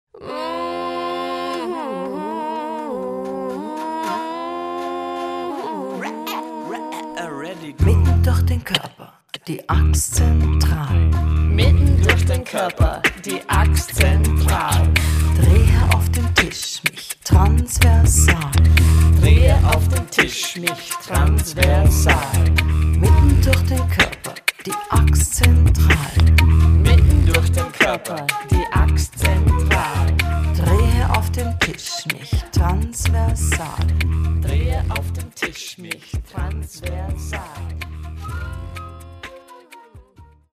vocals, guitar, percussion, ocarina
vocals, percussion, human beatbox, didgeridoo
Recorded at: Kinderzimmer Studios